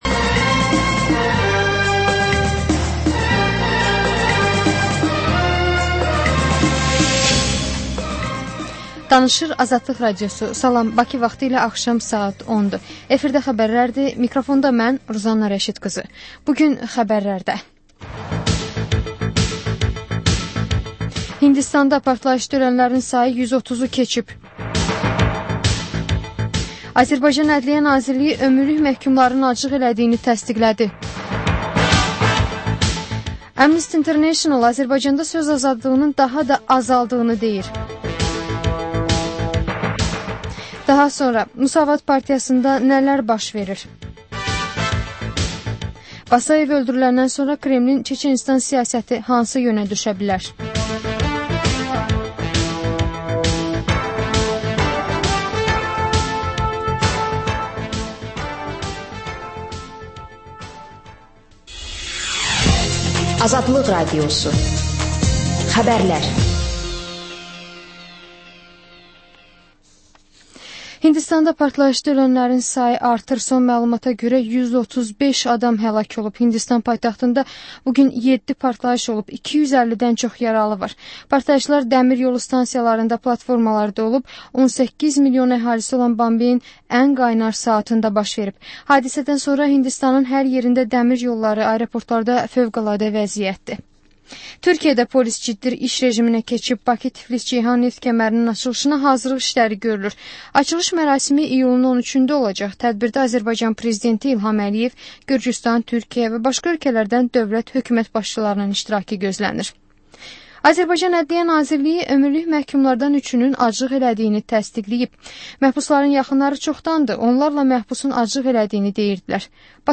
Xəbərlər, reportajlar, müsahibələr. Və: Şəffaflıq: Korrupsiya barədə xüsusi veriliş.